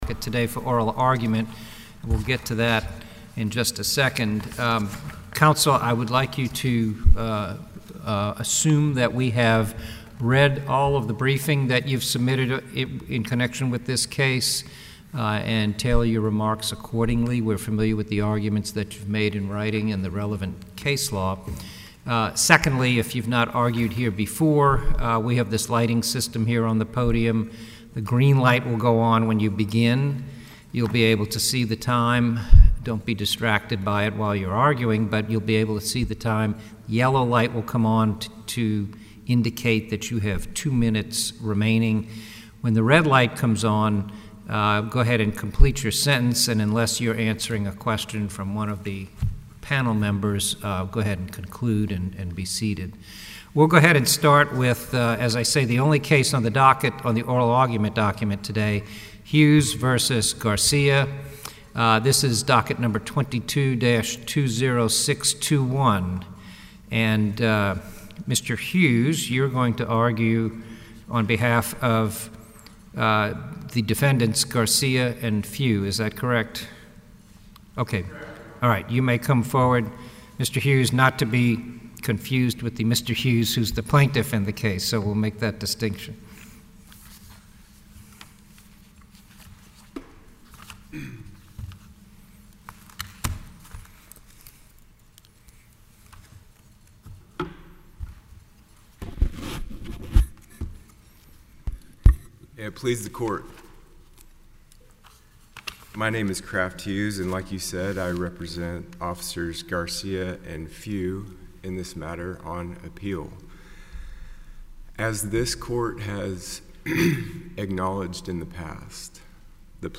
In oral arguments before the 5th Circuit last December, an attorney for the city of Houston argued that no such case existed.